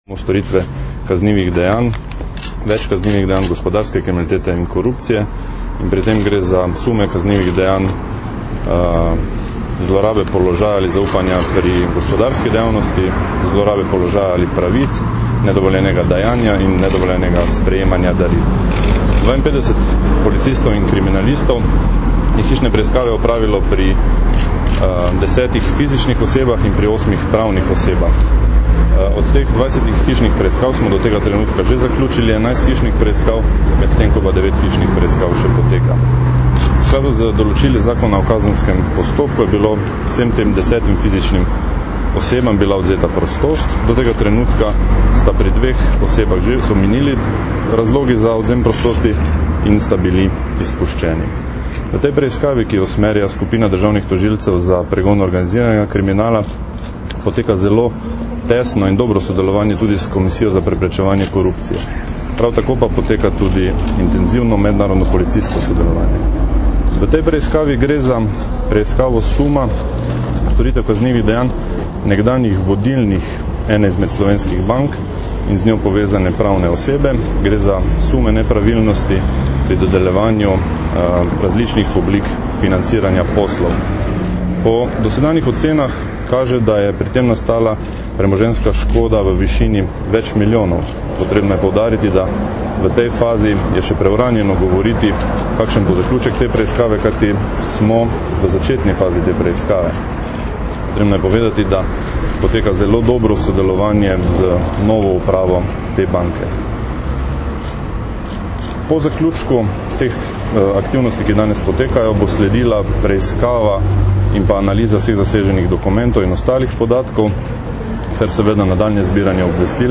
Zvočni posnetek izjave Roberta Črepinka (mp3)